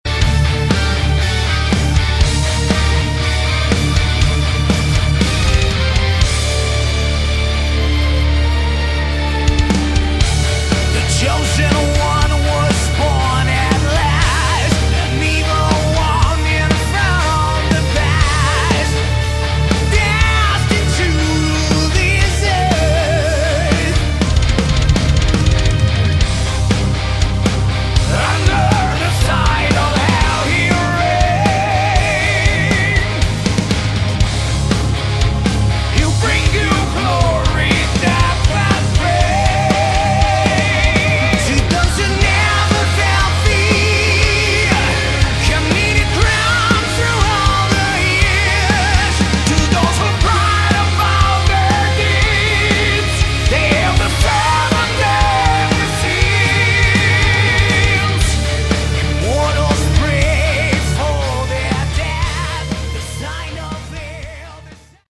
Category: Melodic Metal
vocals
bass, keyboards, backing vocals
guitars
drums